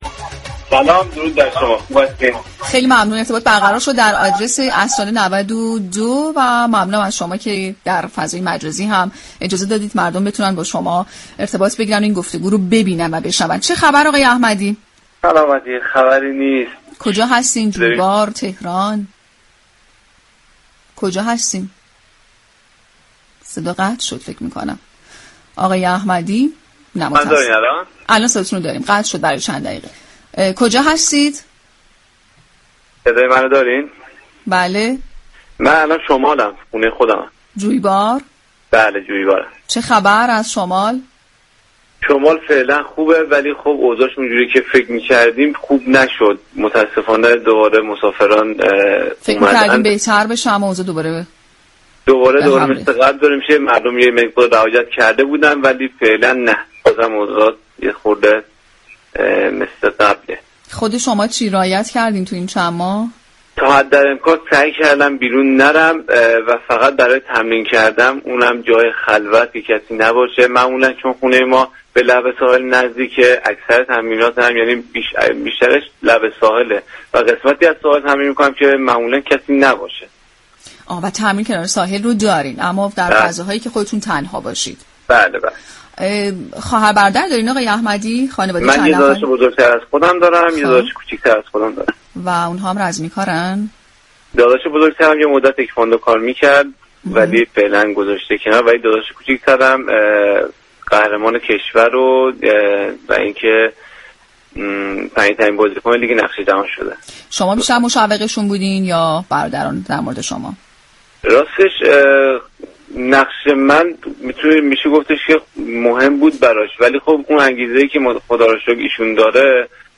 عصرانه، برنامه عصرگاهی رادیو ورزش است كه با رویكرد كارشناسی، تحلیلی و نمایشی هر روز به جز جمعه ها ساعت 17 به مدت 1 ساعت روی آنتن می رود.